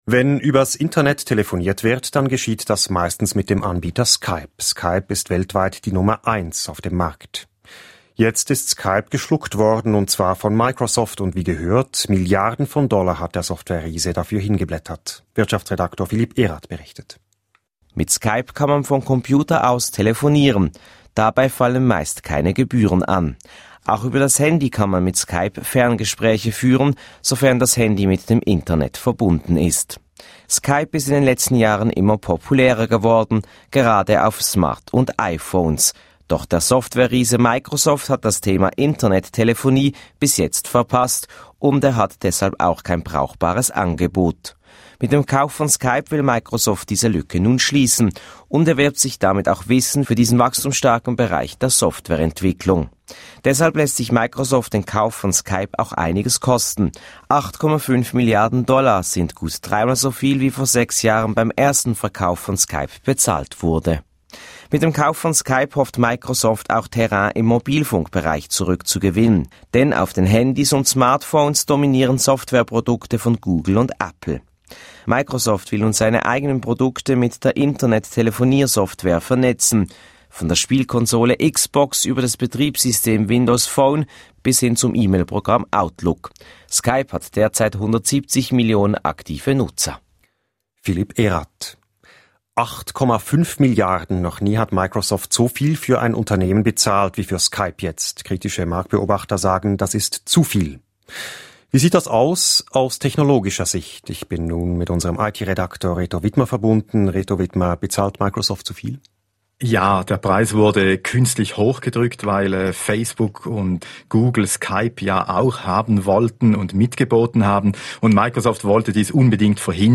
Bericht
Gespräch